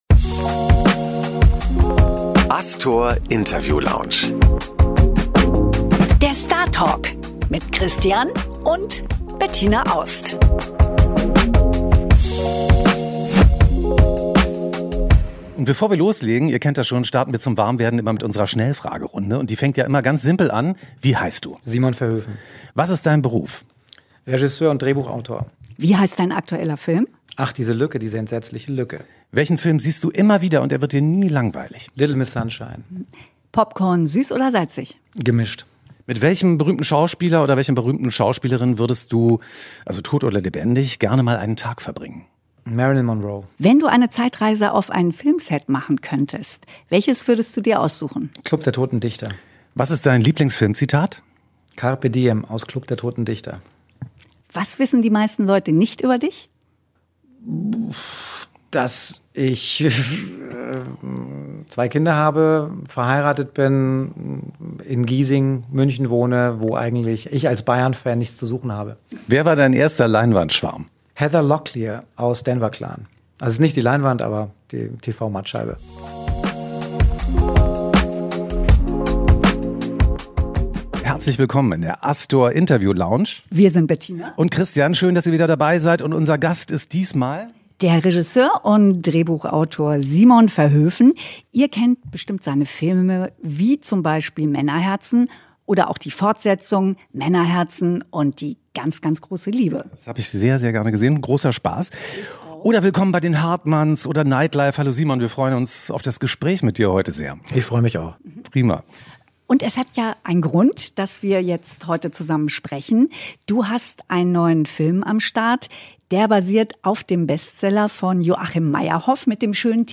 Im Podcast Astor Interview Lounge begrüßen wir diesmal den Regisseur und Drehbuchautor Simon Verhoeven!